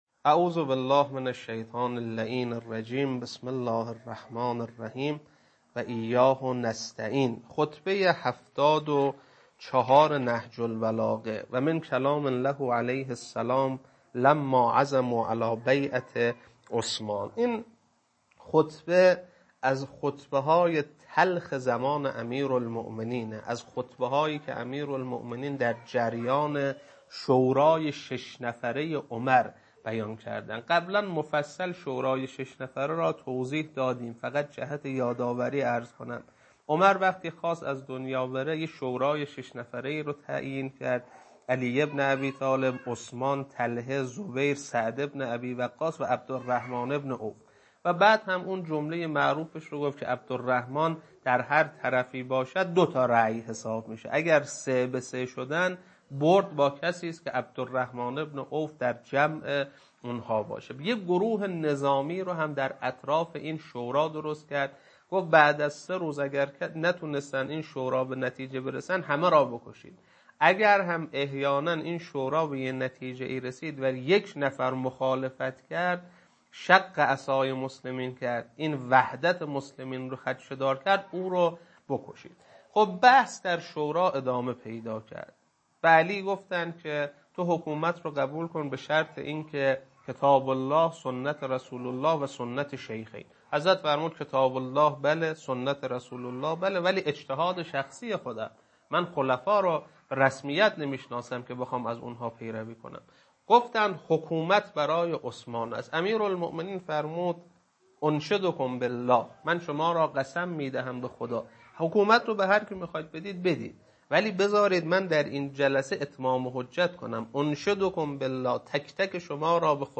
خطبه-74.mp3